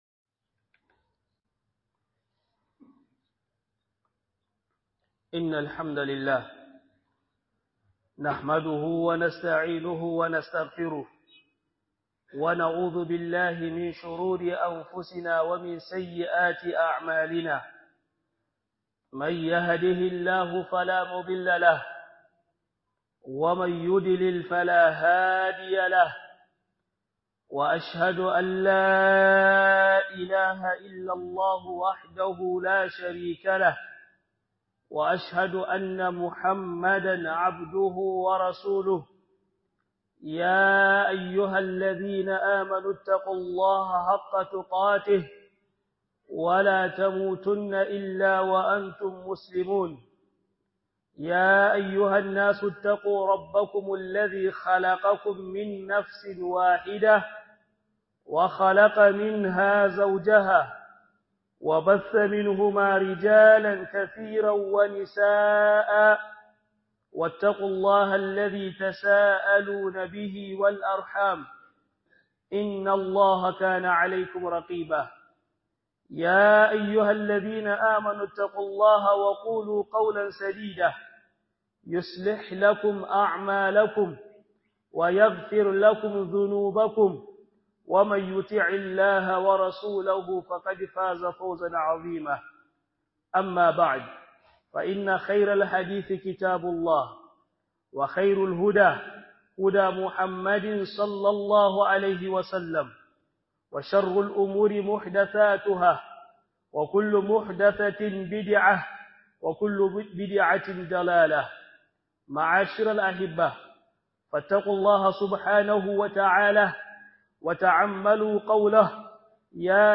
HUDUBA